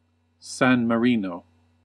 1. ^ /ˌsæn məˈrn/
SAN mə-REE-noh, Italian: [sam maˈriːno]; Romagnol: San Maréin or San Maroin
En-us-San_Marino.ogg.mp3